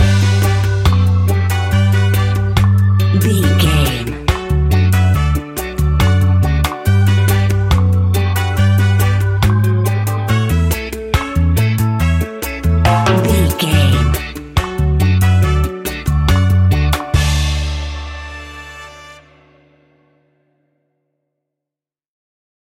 Classic reggae music with that skank bounce reggae feeling.
Ionian/Major
Slow
laid back
chilled
off beat
drums
skank guitar
hammond organ
percussion
horns